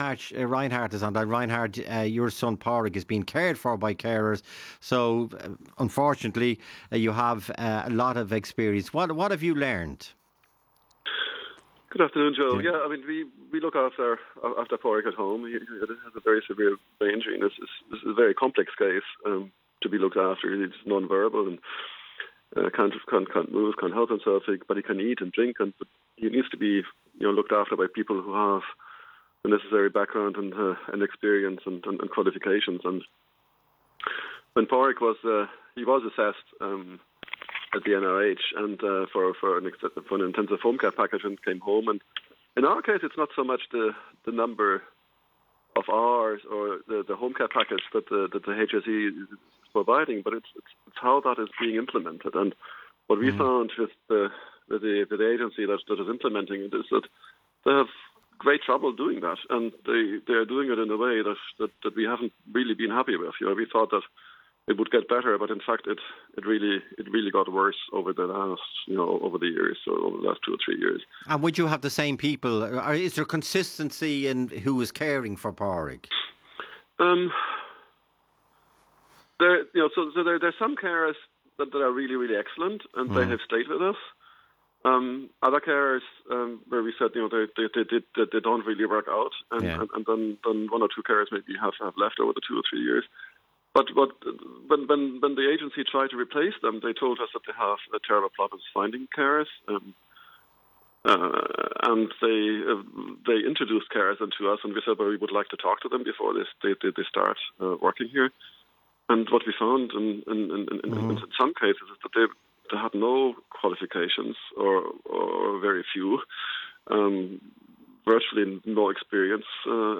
This is what Liveline, Ireland’s most popular phone-in show, posted today about carers: